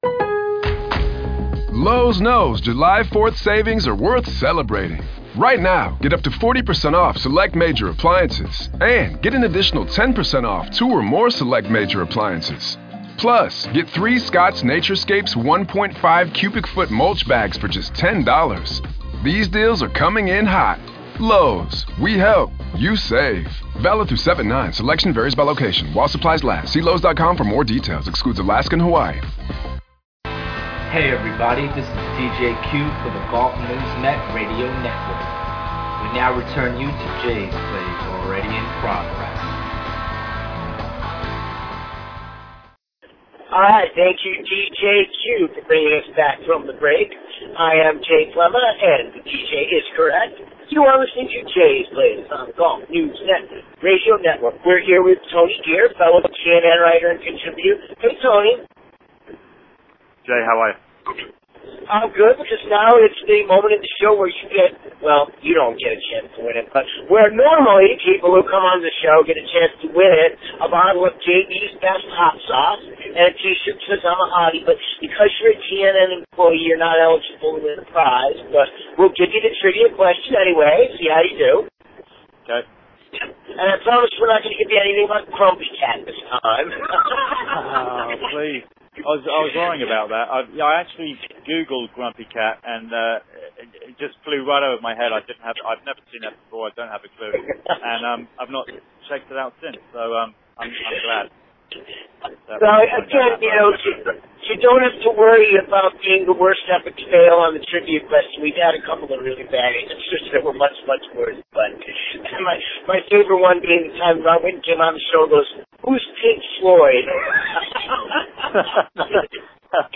two-part conversation in preparation of the Masters, bantering about other important topics in the game.